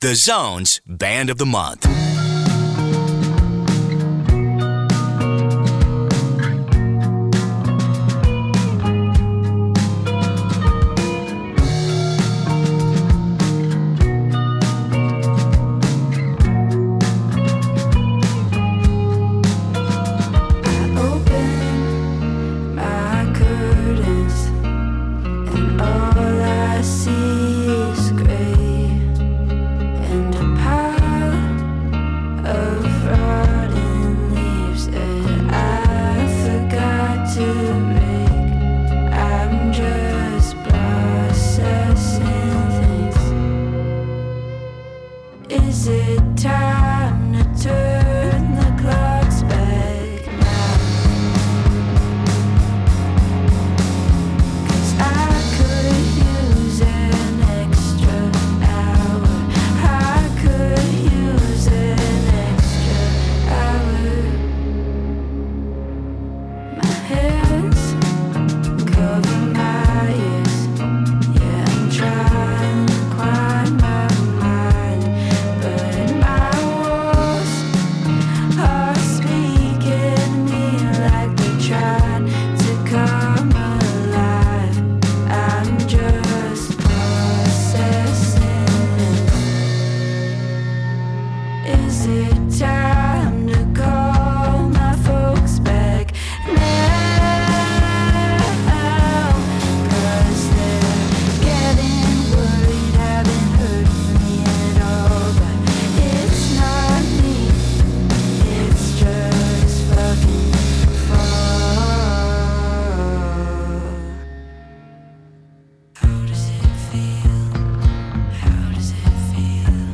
vocals, guitar
guitar, bass, synth
violin, piano
indie
and hope through relatable lyrics and emotive soundscapes.